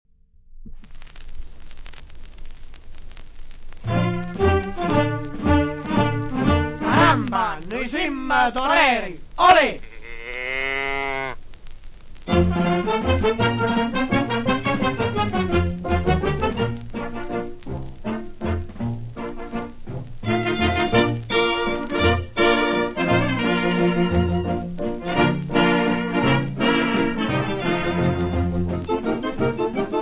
• musica da ballo
• registrazione sonora di musica